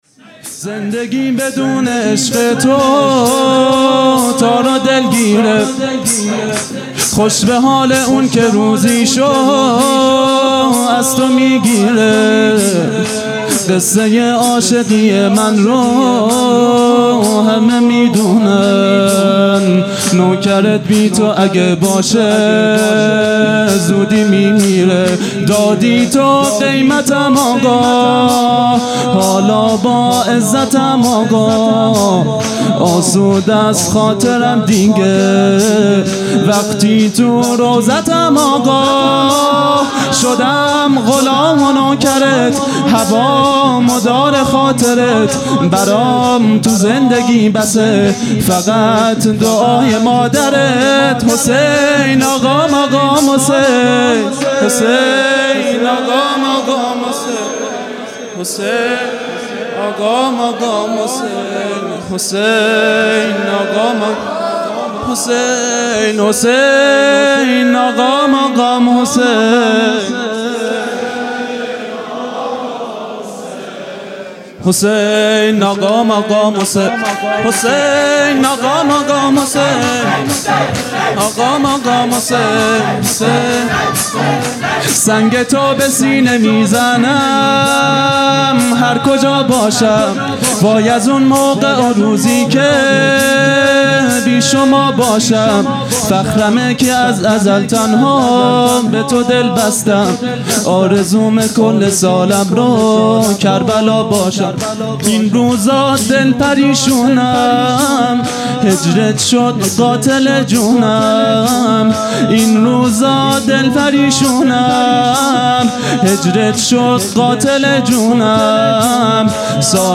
خیمه گاه - هیئت بچه های فاطمه (س) - شور | زندگیم بدون عشق تو
محرم ۱۴۴۱ |‌ شب پنجم